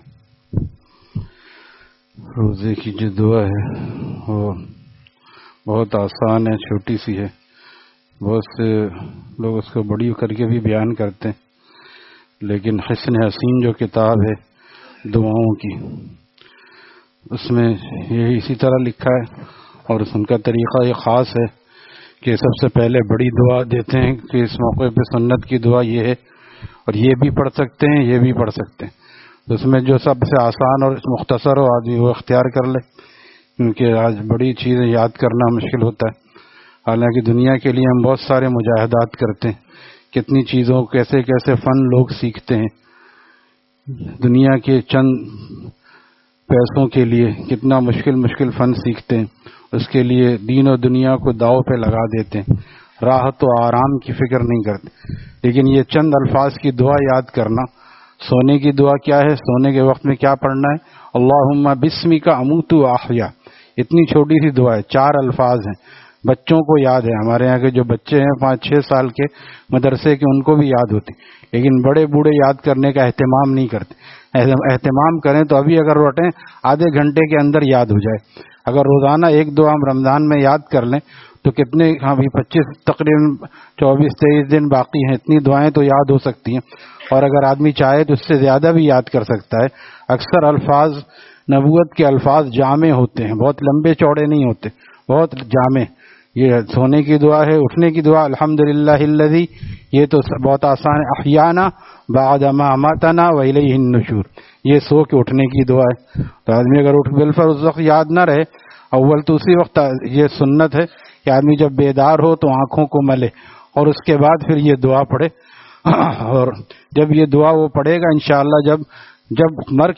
Taleem After Fajor at Jamia Masjid Gulzar e Muhammadi, Khanqah Gulzar e Akhter, Sec 4D, Surjani Town